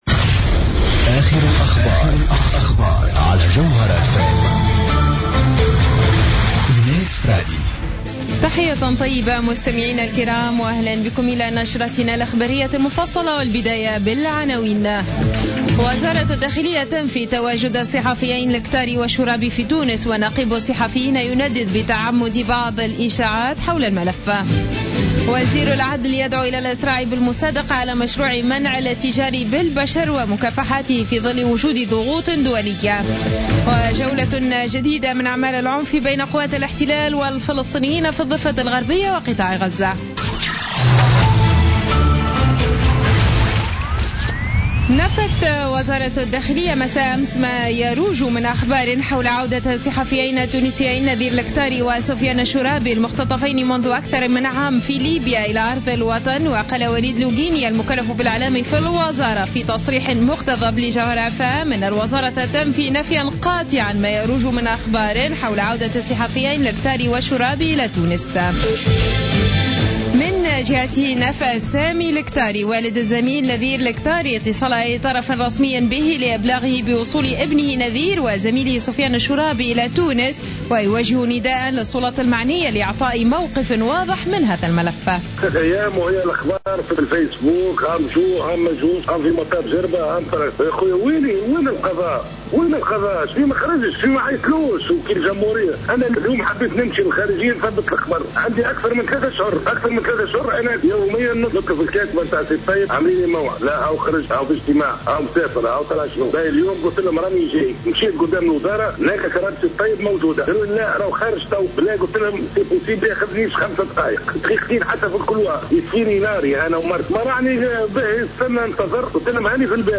نشرة أخبار منتصف الليل ليوم السبت 17 أكتوبر 2015